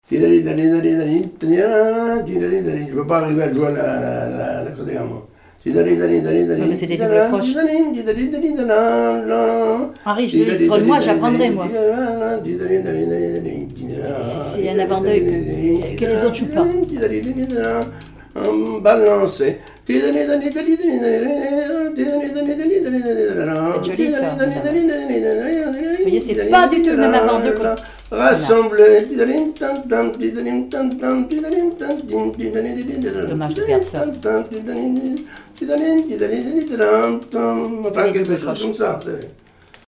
danse : branle : avant-deux ; danse : pas d'été ;
Répertoire de marches de noces et d'avant-deux
Pièce musicale inédite